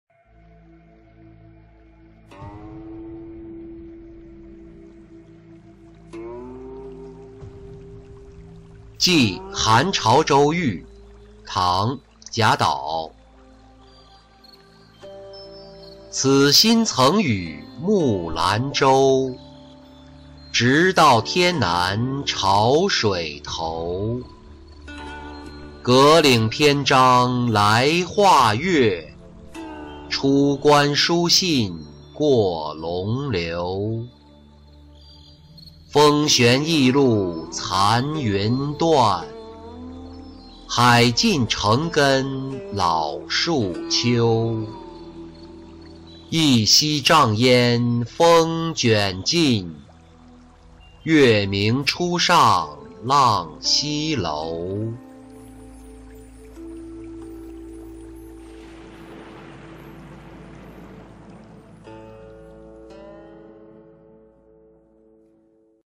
寄韩潮州愈-音频朗读